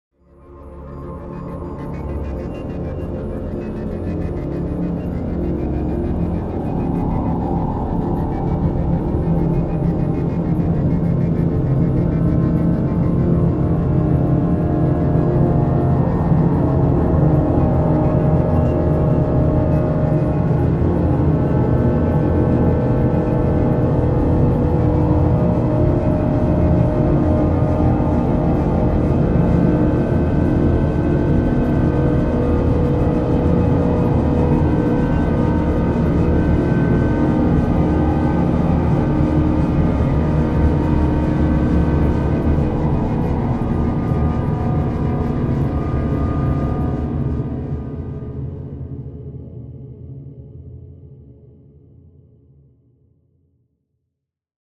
ATMOSPHERIC DARK Fluttering Darkness.wav